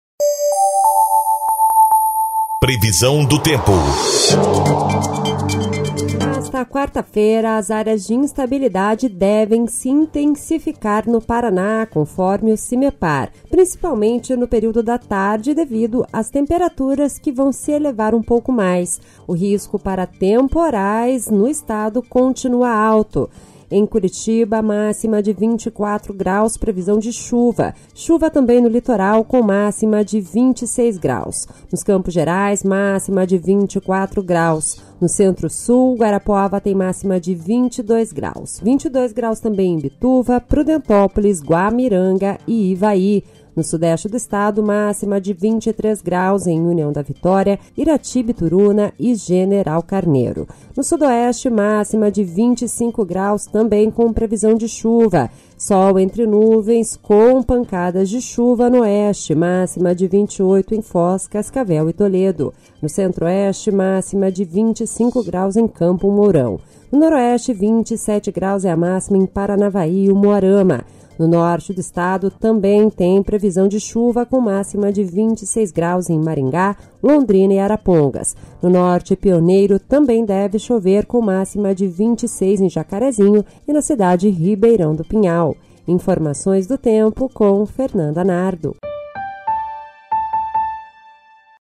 Previsão do Tempo (22/02)